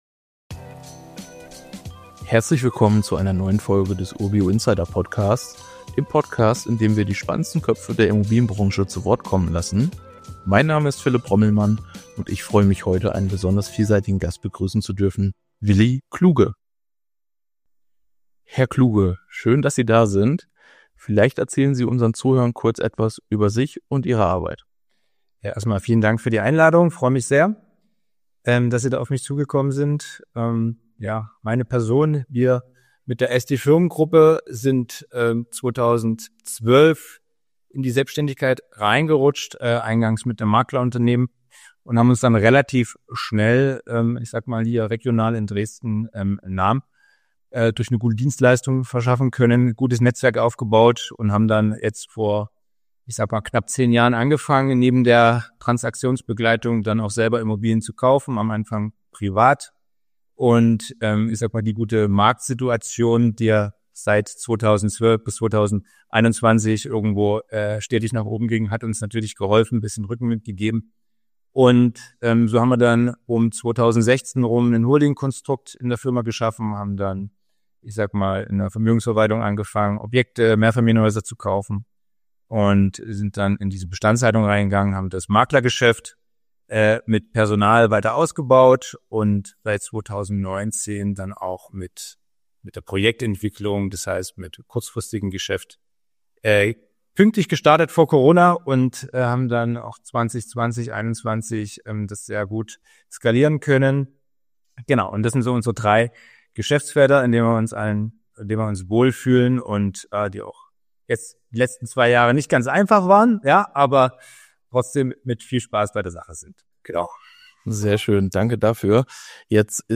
Unser Gast, ein erfahrener Investor und Projektentwickler, spricht über die aktuellen Markttrends, die Herausforderungen durch steigende Zinsen und den Einfluss von Digitalisierung und Nachhaltigkeit. Erfahren Sie, welche Stadtteile das größte Potenzial bieten und wie Netzwerke den Erfolg sichern können.